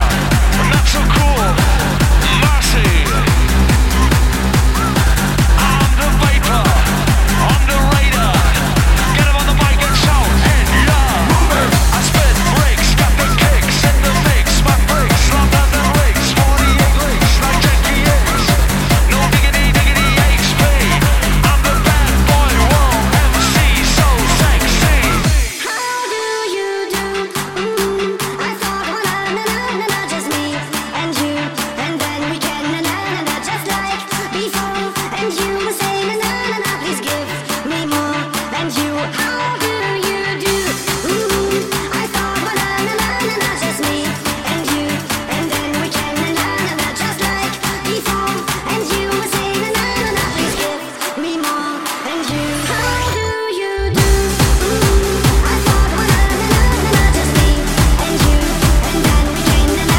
Электронная
Лучшая танцевальная музыка!